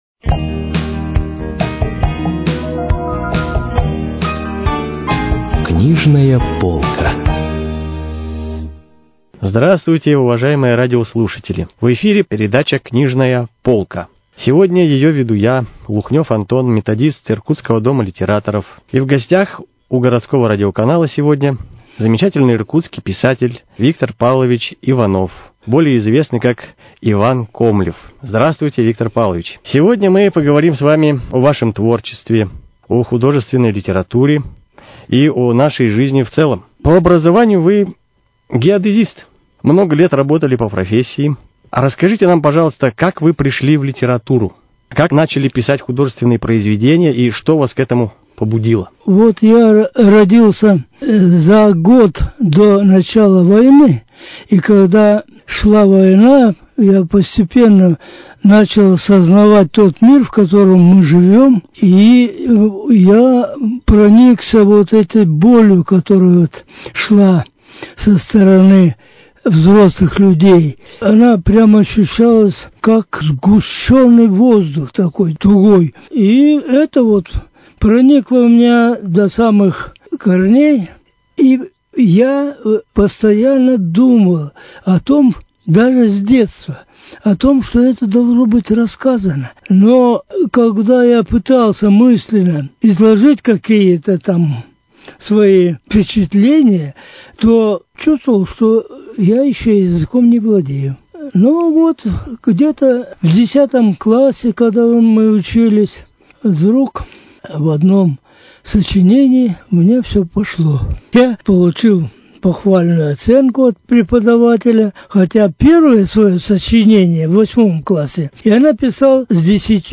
В передаче писатель ответил на вопросы о своём творчестве: когда начал писать прозу, какие писатели повлияли на его личность и творчество, о реальной основе своих сюжетов. Автор считает тему Великой Отечественной войны неисчерпаемой для прозы.